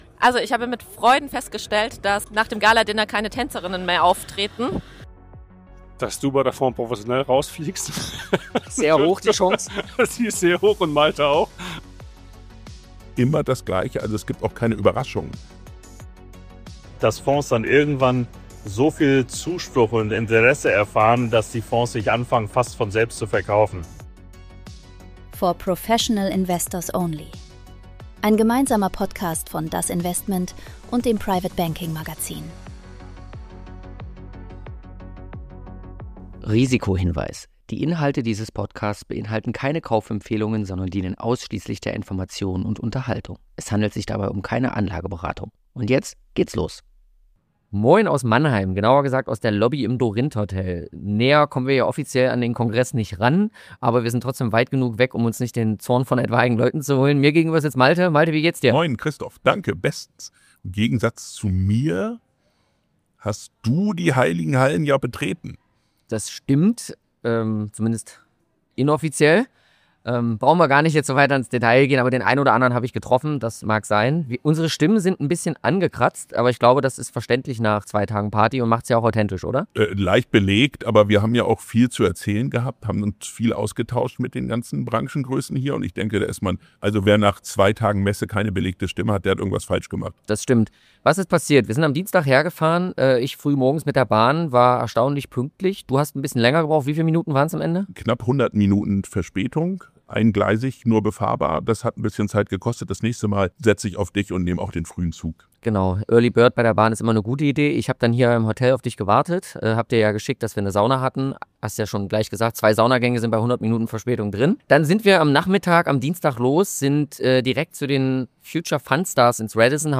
In dieser Folge haben wir Stimmen im Rosengarten eingefangen, abseits der großen Bühnen und Präsentationen. Gespräche zwischen Terminen, Kaffeeschlange und nächstem Meeting.